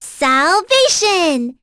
Rephy-Vox_Skill2.wav